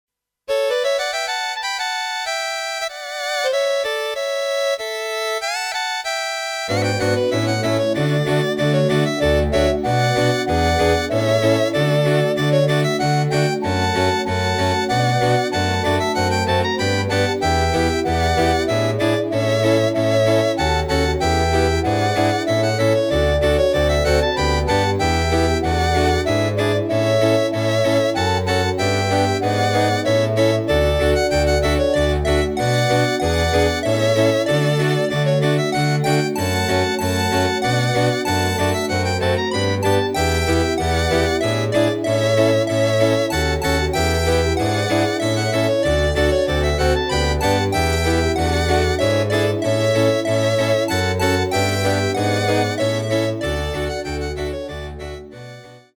Rubrika: Vánoční písně, koledy